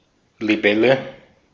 Ääntäminen
US : IPA : [ˈdɹæɡ.ən.ˌflaɪ]